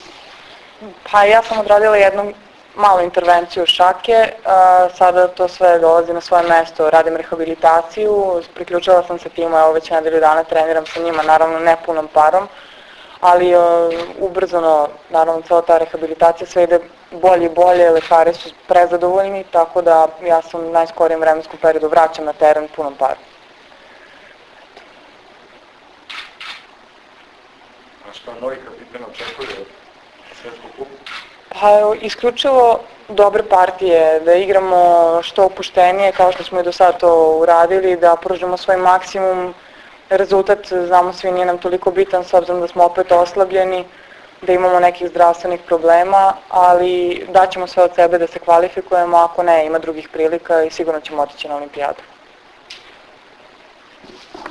Tim povodom danas je u beogradskom hotelu “M” održana konferencija za novinare
IZJAVA